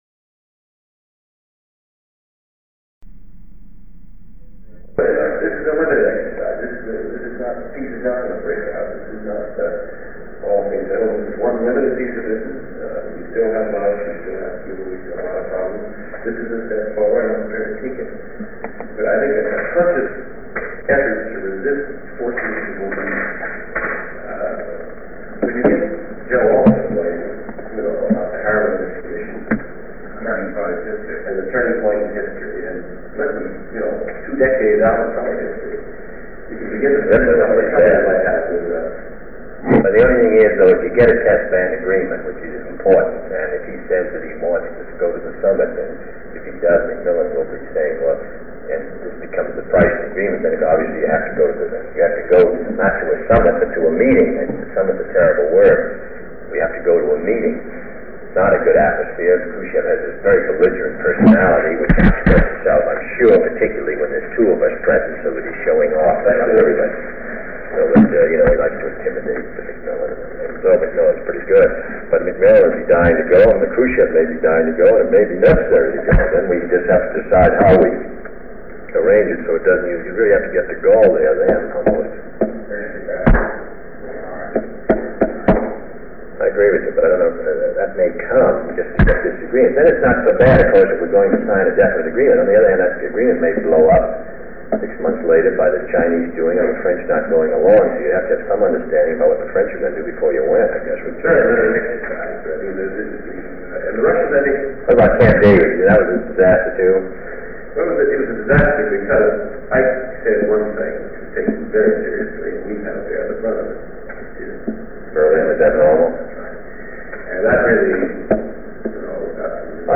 Sound recording of a meeting held on July 11, 1963, between President John F. Kennedy, Special Assistant to the President for National Security Affairs McGeorge Bundy, and Deputy Special Assistant to the President Walt Rostow. They discuss a possible meeting on nuclear test ban negotiations with Soviet Premier Nikita S. Khrushchev, French President Charles DeGaulle, and British Prime Minister Harold Macmillan.